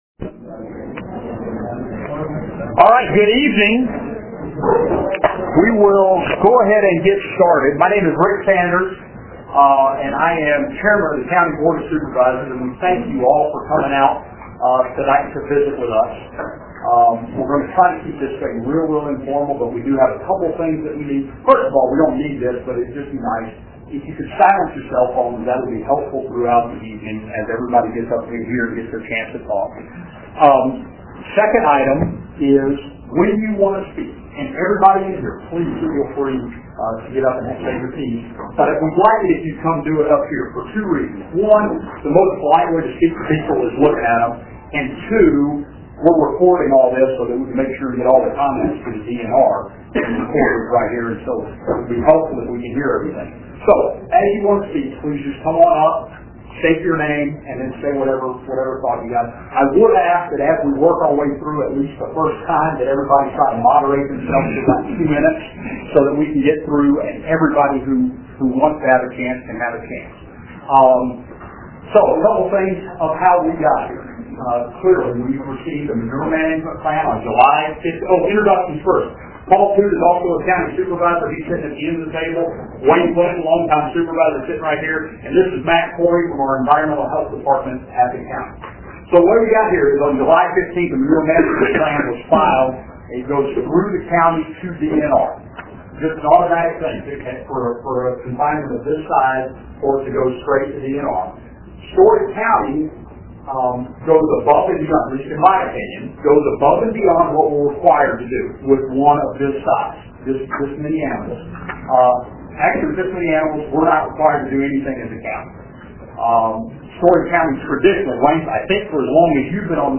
Mud Creek Feeders Public Meeting; Collins Iowa
A public meeting was held on Monday, August 5, 2013 in Collins Iowa regarding the proposed Mud Creek Feeders Hog Confinement.